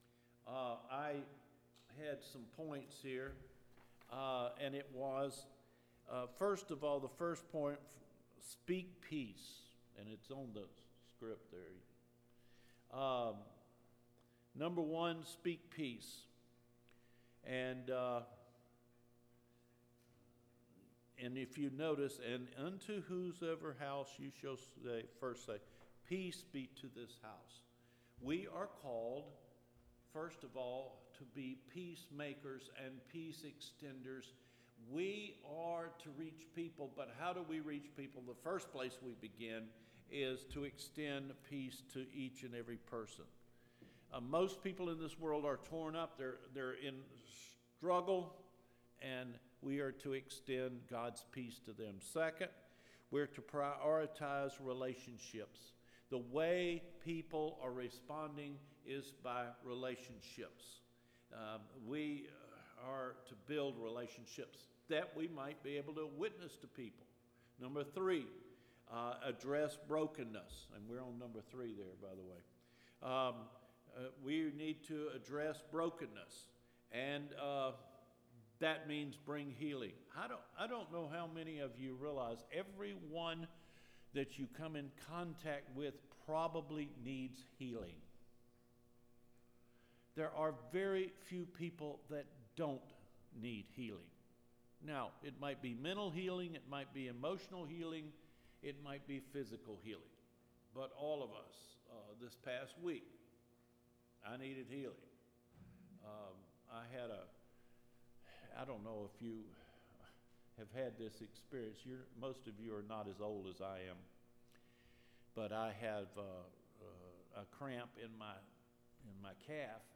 FEBRUARY 28 SERMON – ENCOUNTERING THE JOY OF JESUS’ LOVE